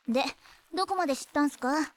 sukasuka-anime-vocal-dataset